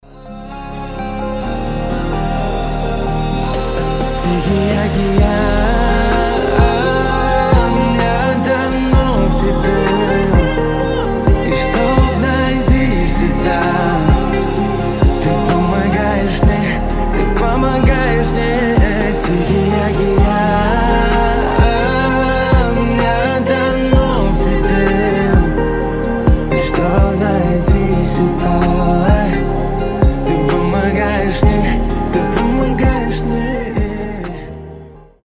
Лирика Медленные Красивый мужской голос Дуэт